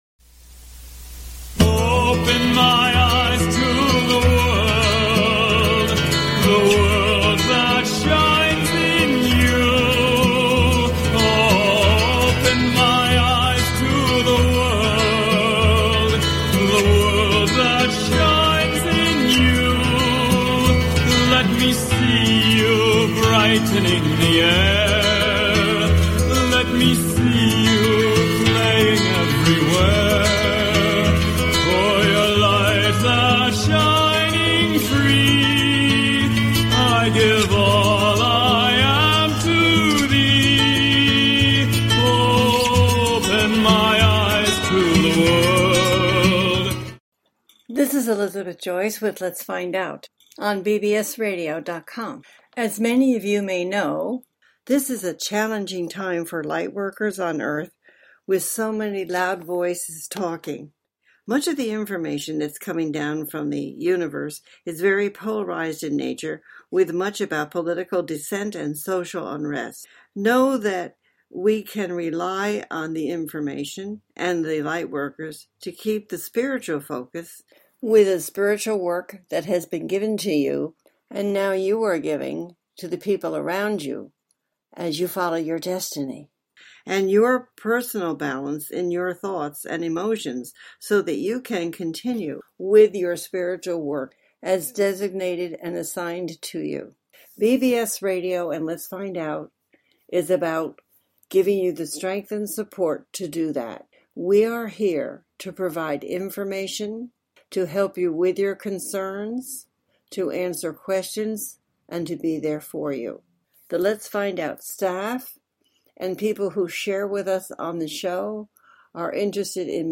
The listener can call in to ask a question on the air.
This is a call in show.